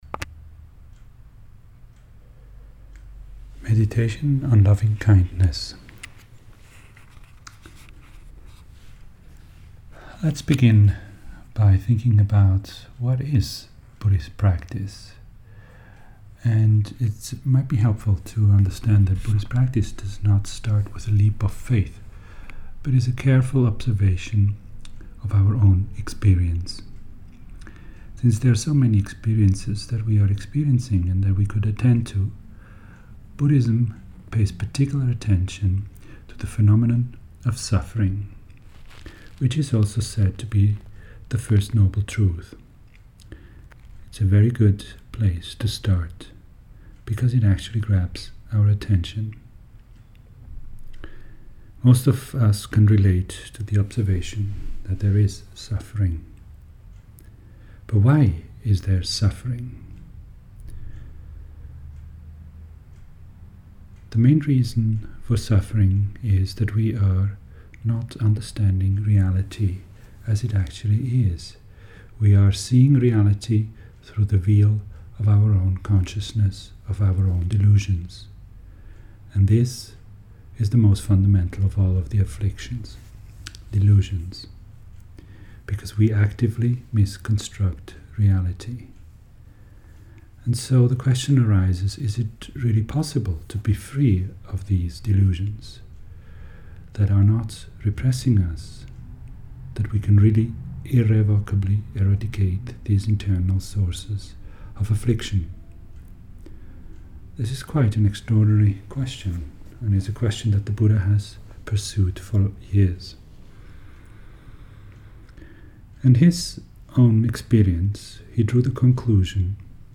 box_xii_Loving_Kindness_Meditation.mp3